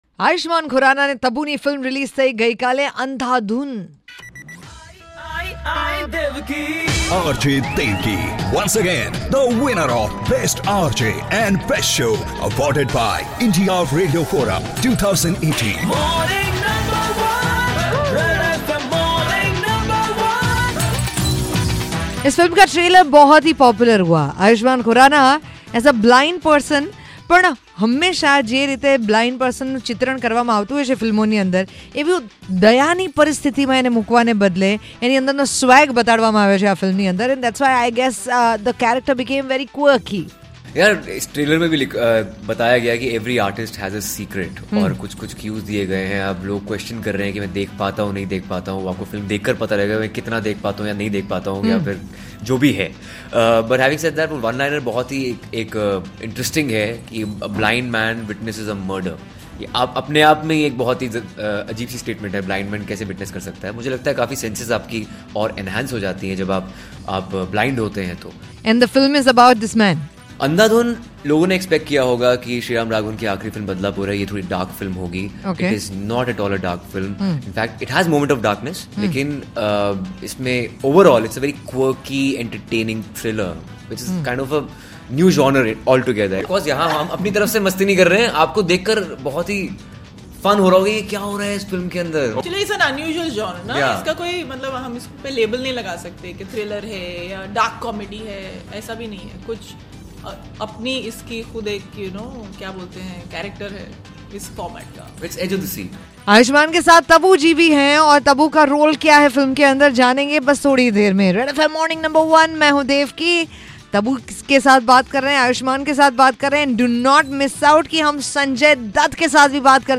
10.1- Interview with cast of Andhadhun